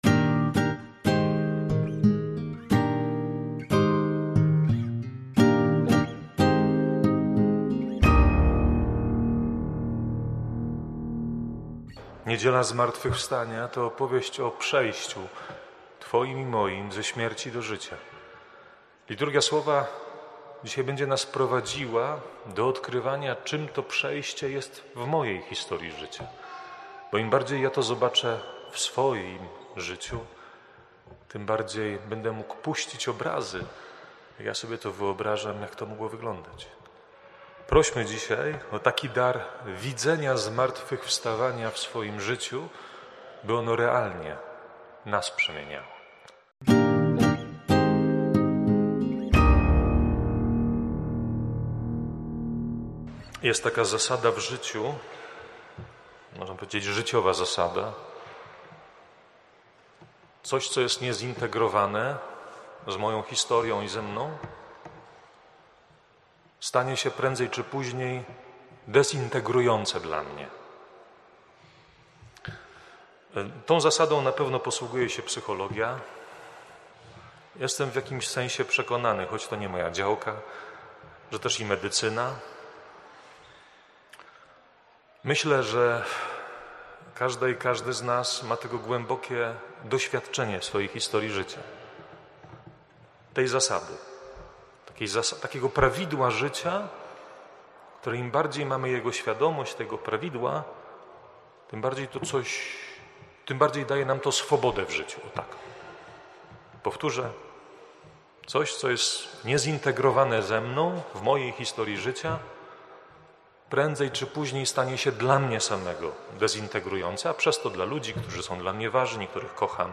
wprowadzenie do Liturgii, oraz kazanie: